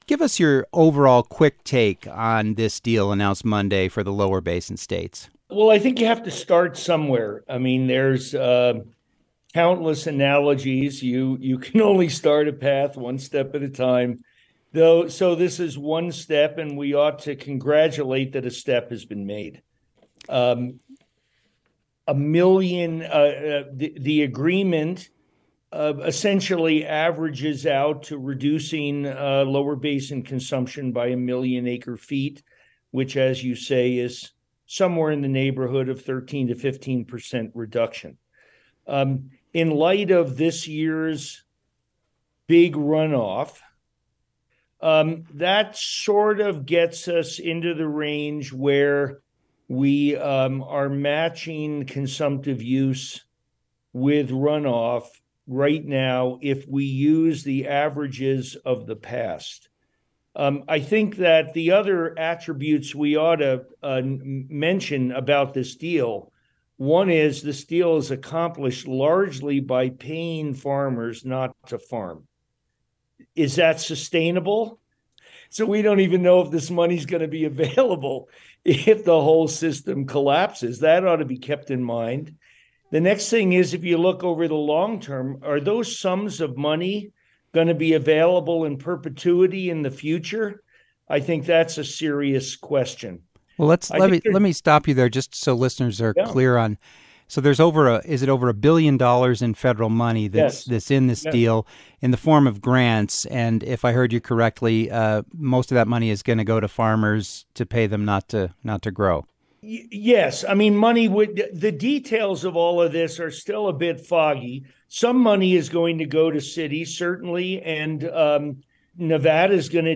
Interview Transcript: